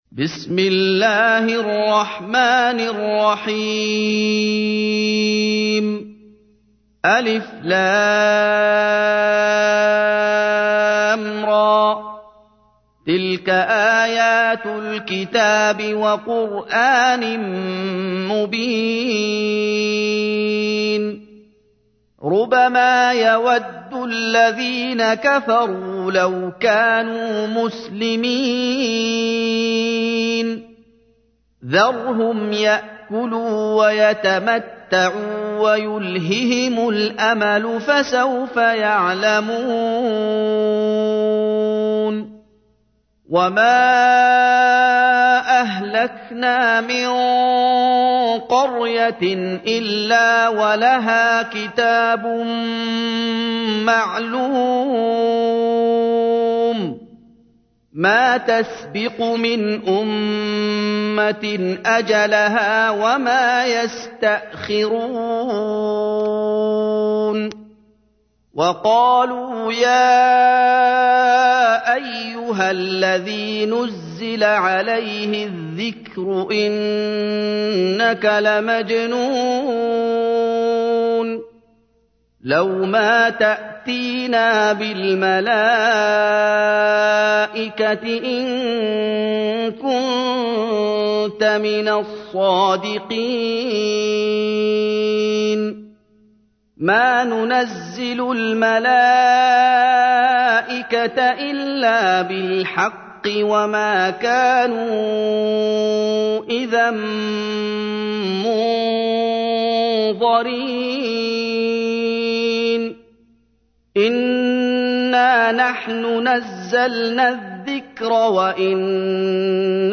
تحميل : 15. سورة الحجر / القارئ محمد أيوب / القرآن الكريم / موقع يا حسين